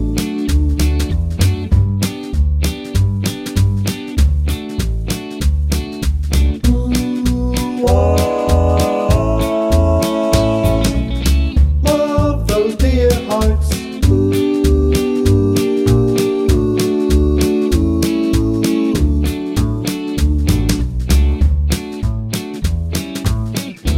Live Version Easy Listening 1:47 Buy £1.50